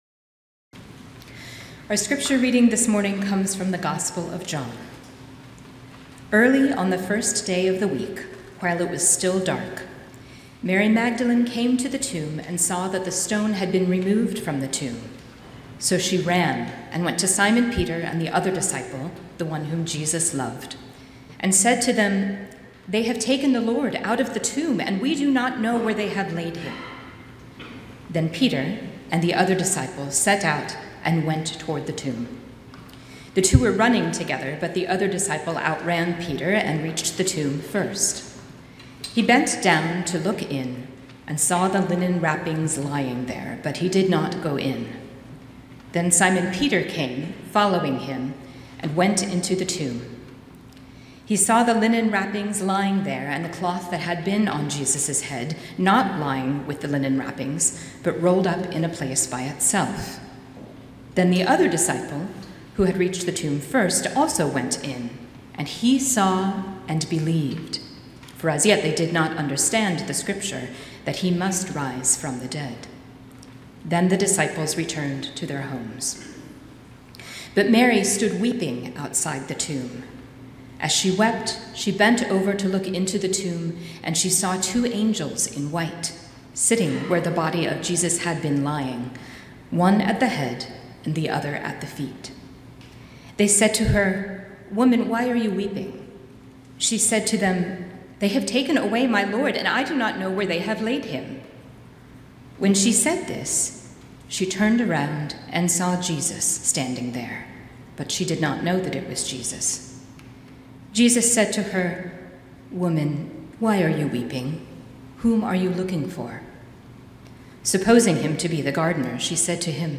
Sermon-April-5-2026-Christ-is-with-Us-Resurrection.mp3